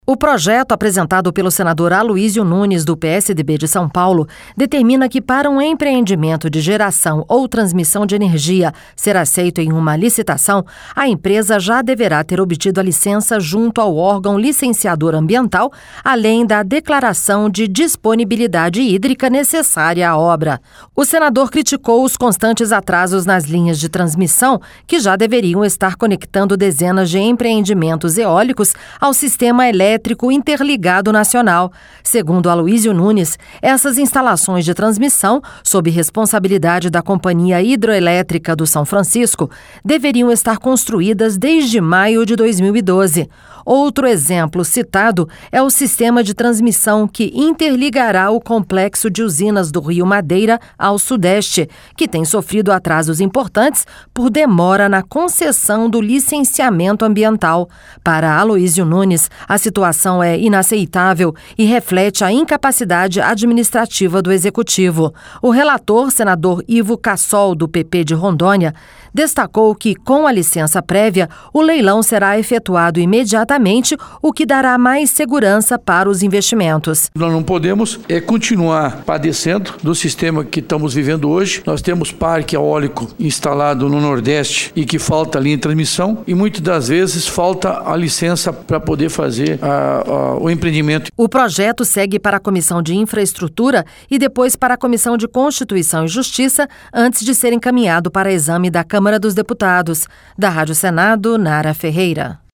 Senador Ivo Cassol